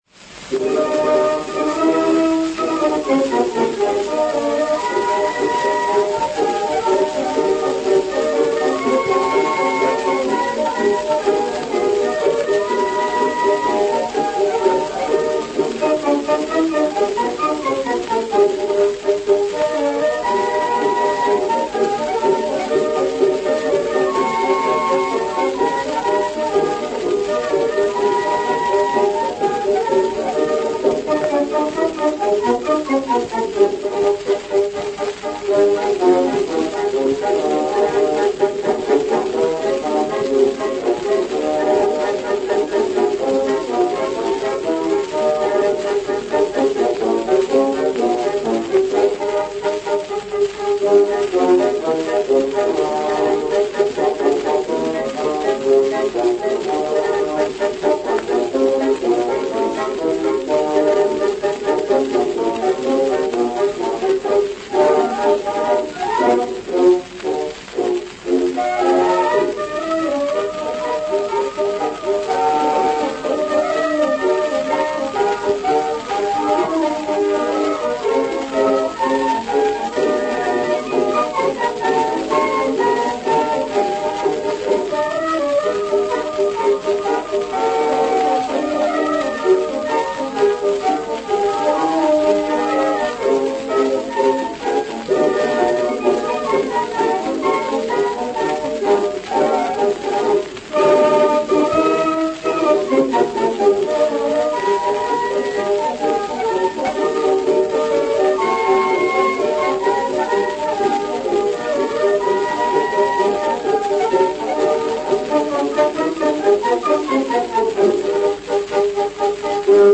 «Тоска по Родине» (грамзапись Zonophone-X-60713 — июнь 1906 года) (скачать)
Оба марша минорные и — невероятно похожие: при прослушивании этих двух произведений одного за другим остаётся стойкое впечатление, что они — части единого целого.